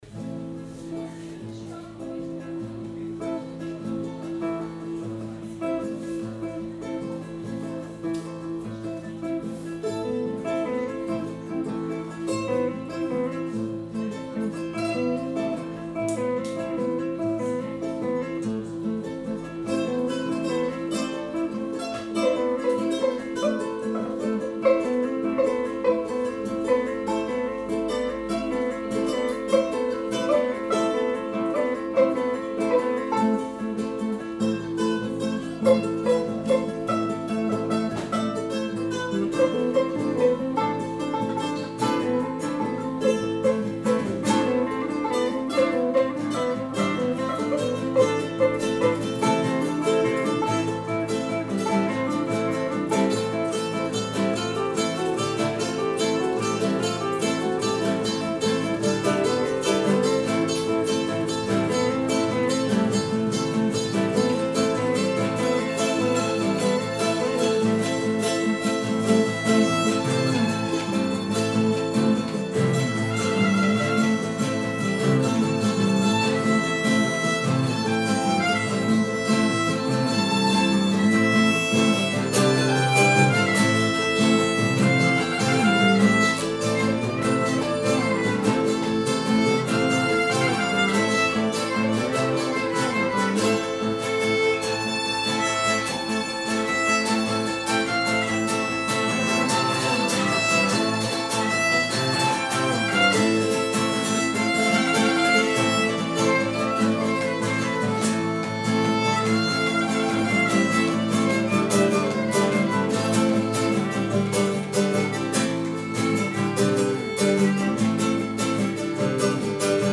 pequenas apresentações em casas de amigos e conhecidos.